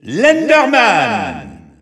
The announcer saying Enderman's name in French.
Category:Steve (SSBU) Category:Announcer calls (SSBU) You cannot overwrite this file.
Enderman_French_EU_Alt_Announcer_SSBU.wav